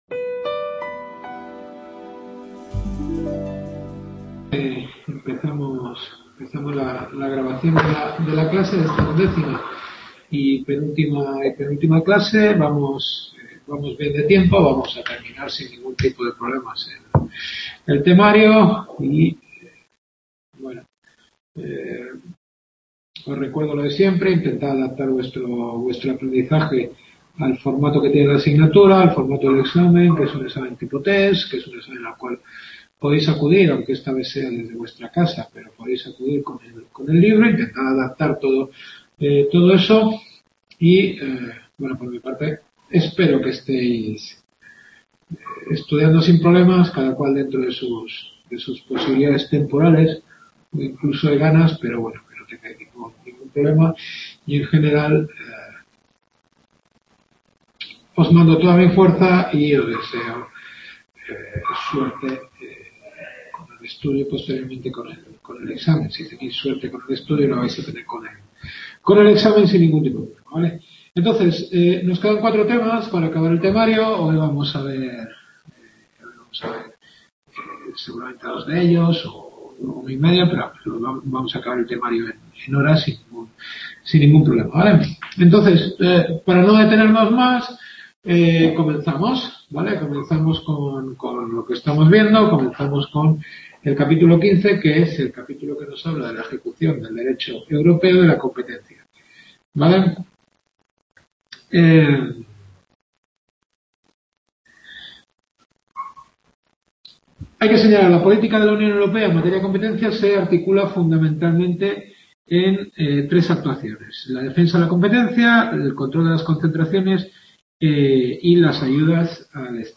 Derecho administrativo europeo. Undécima clase.